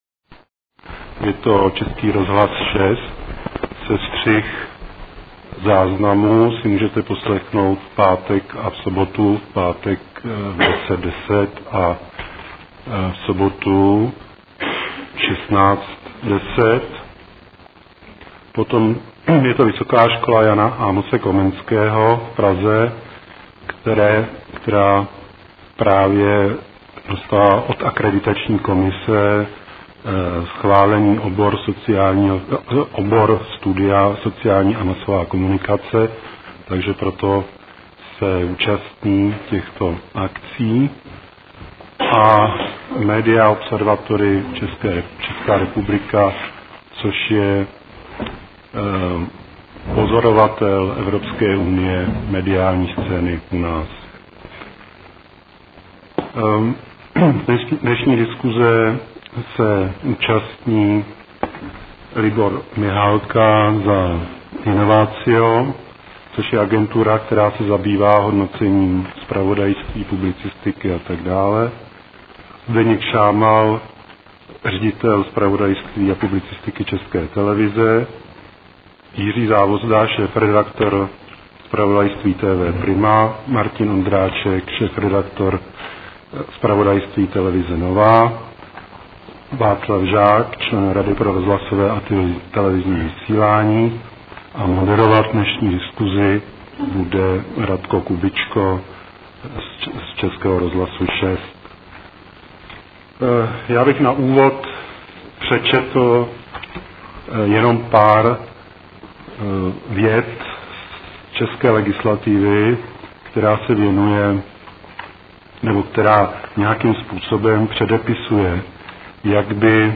Na podobnou otázku ve slušivém postmoderně mediálním oblečku se pokoušeli odpovědět teoretici, dozorci, analytici, praktici a kecalové všeho druhu, včetně mne. Server Česká média uspořádal spolu s Českým rozhlasem 6, Vysokou školou J. A. Komenského Praha a uskupením Media Observatory ČR diskusní podvečer na téma LZE MĚŘIT NESTRANNOST (ZPRAVODAJSTVÍ)?